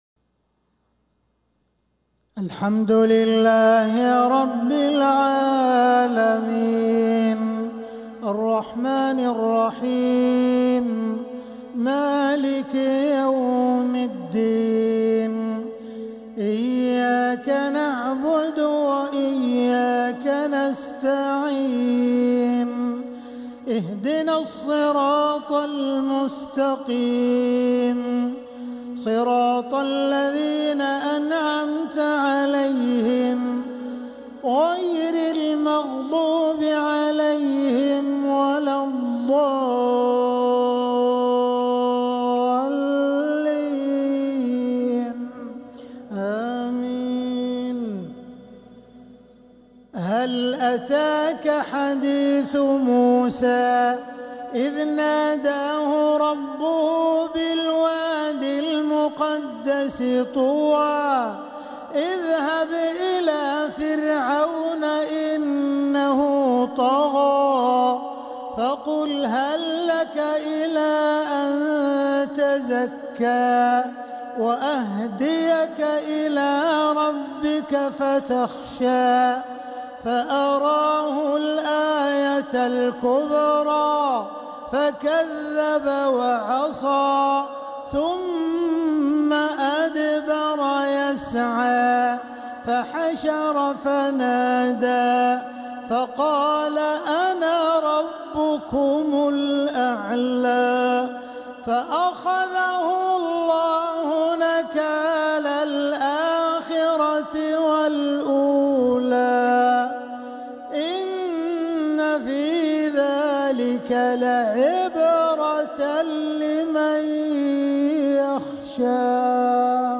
مغرب السبت 10 محرم 1447هـ من سورة النازعات 15-33 | Maghrib prayer from Surat An-Naziat 5-7-2025 > 1447 🕋 > الفروض - تلاوات الحرمين